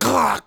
Other Sound Effects
hurt9.wav